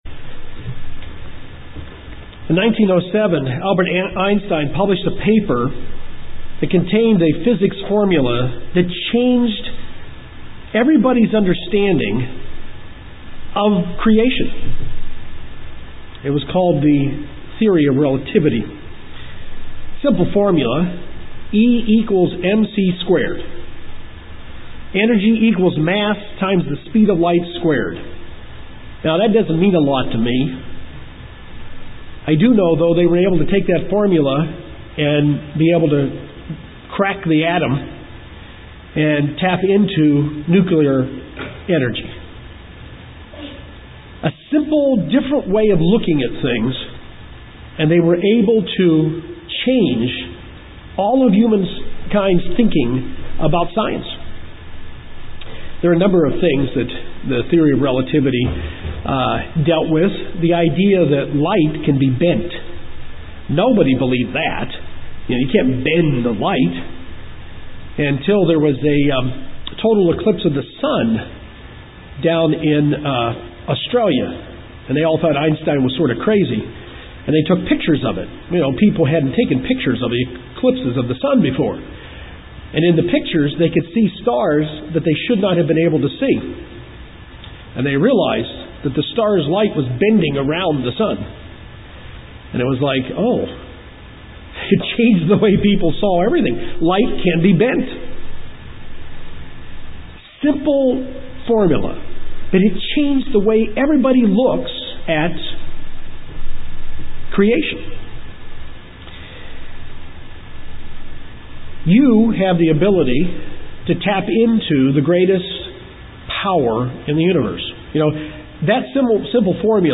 This is the seventh and last sermon on the Fruits of The Spirit.